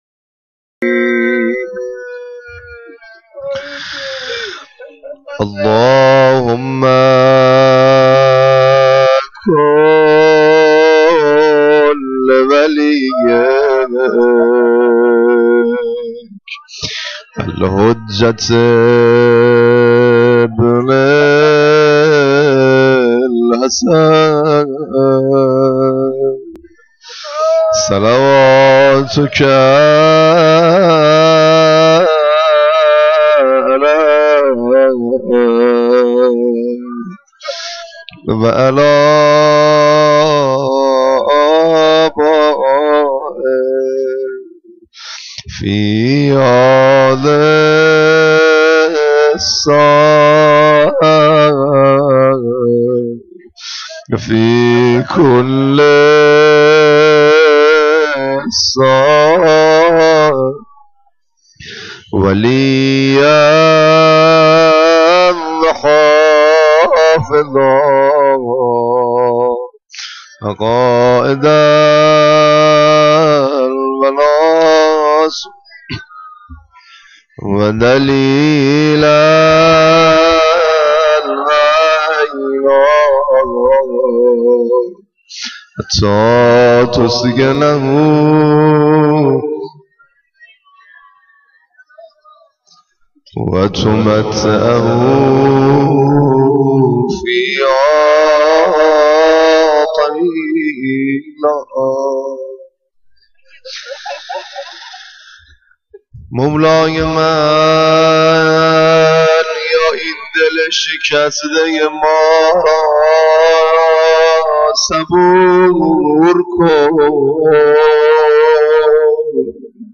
روضه خوانی11.wma
روضه-خوانی11.wma